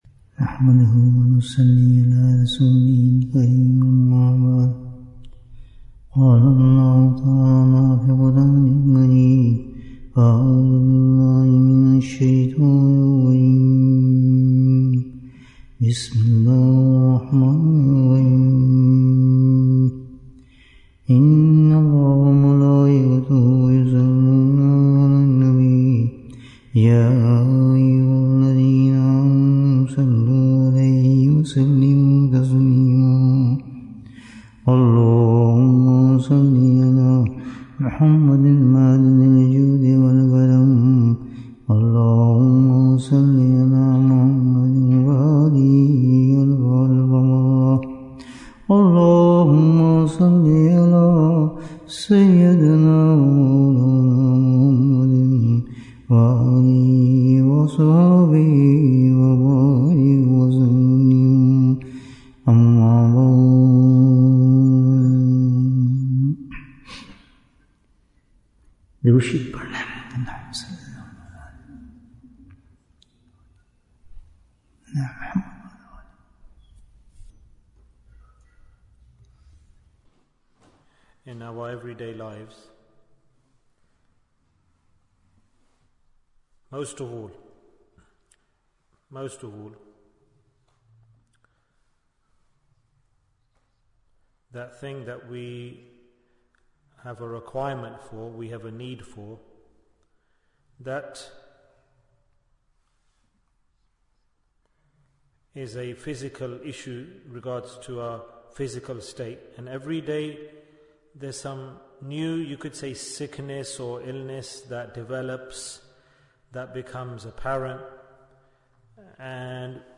How do we Strengthen Our Deen? Bayan, 103 minutes7th November, 2024